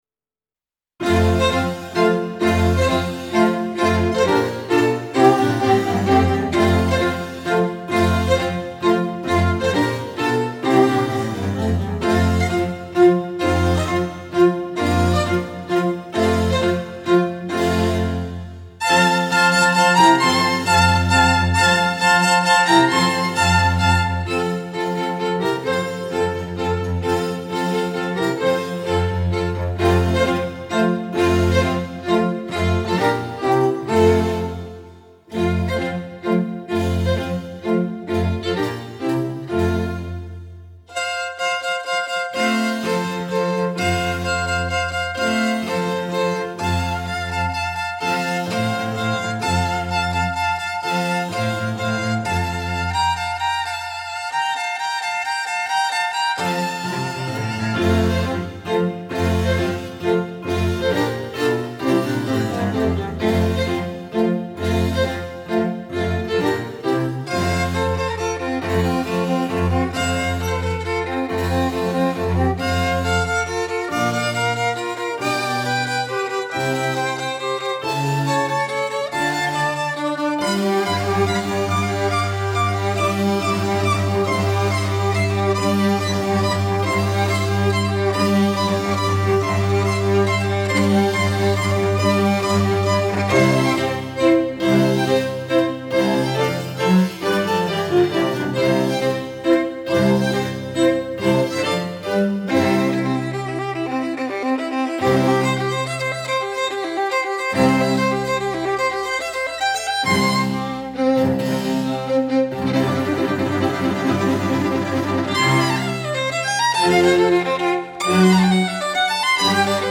Категория: Красивая музыка » Классическая музыка